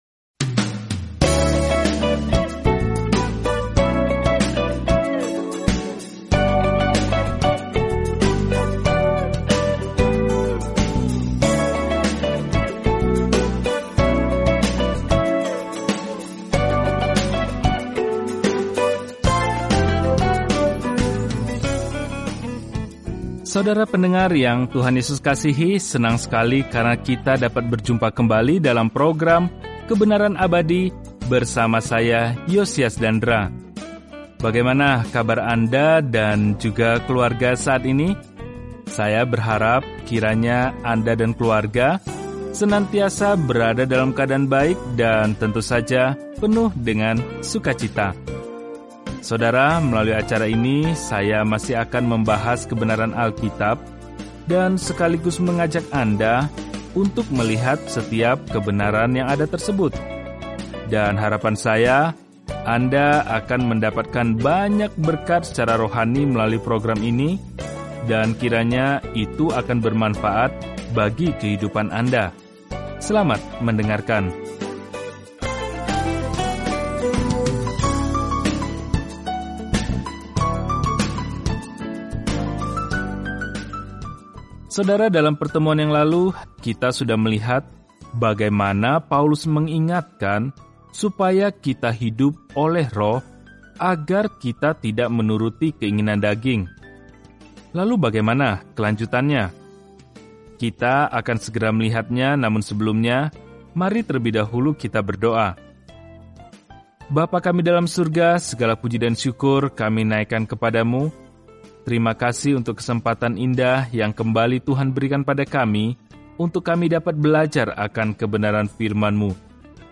Firman Tuhan, Alkitab Galatia 5:17-21 Hari 15 Mulai Rencana ini Hari 17 Tentang Rencana ini “Hanya melalui iman” kita diselamatkan, bukan melalui apa pun yang kita lakukan untuk layak menerima anugerah keselamatan – itulah pesan yang jelas dan langsung dari surat kepada jemaat Galatia. Jelajahi Galatia setiap hari sambil mendengarkan pelajaran audio dan membaca ayat-ayat tertentu dari firman Tuhan.